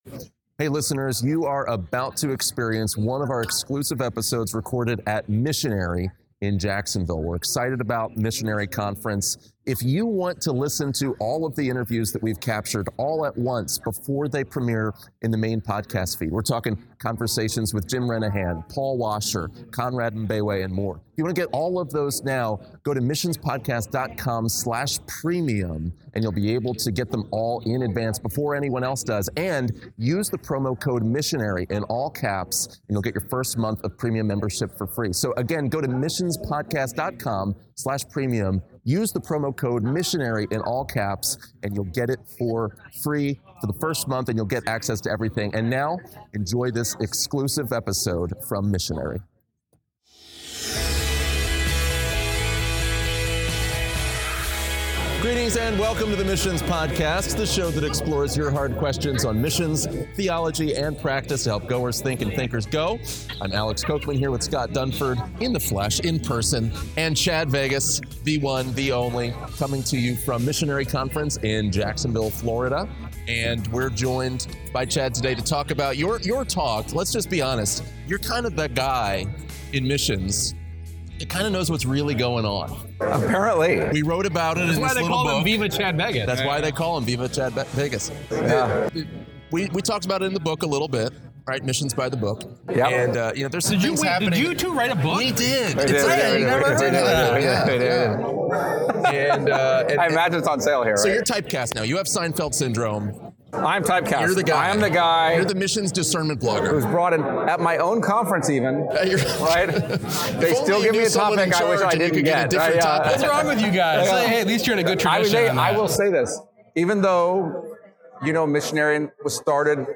What are the main problems in today’s mission world? In this exclusive interview from Missionary